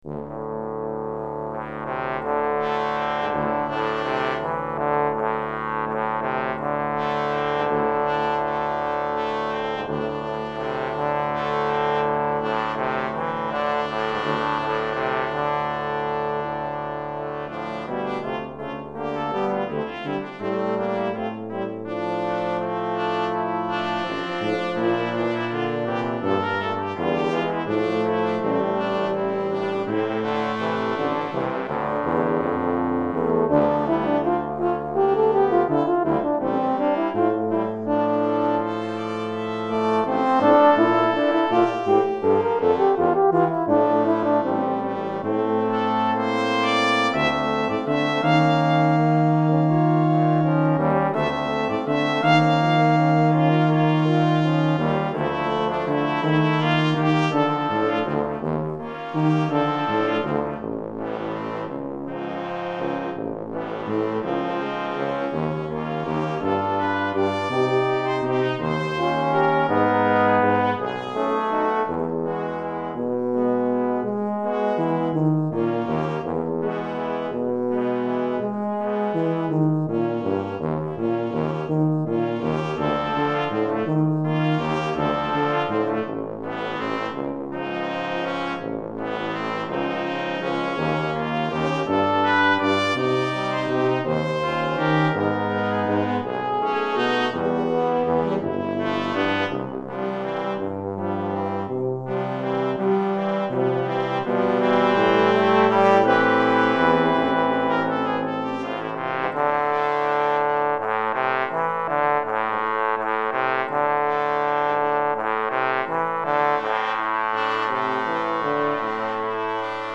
Quintette de Cuivres